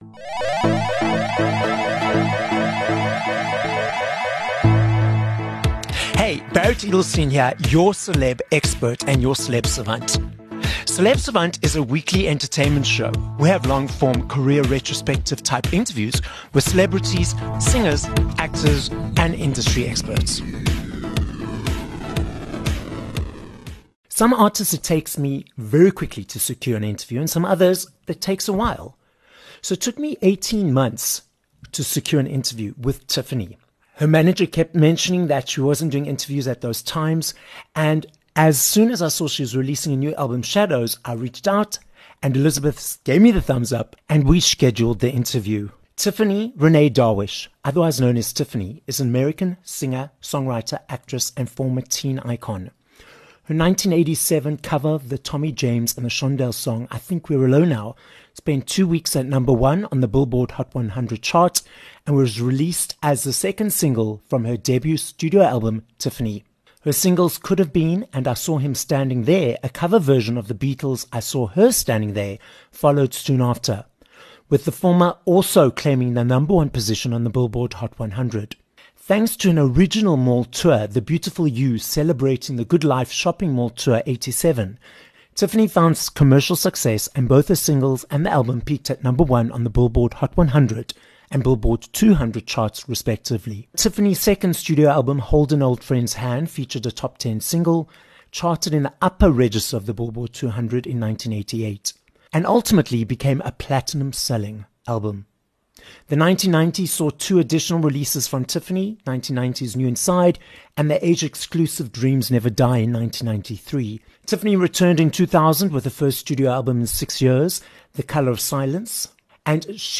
25 Nov Interview with Tiffany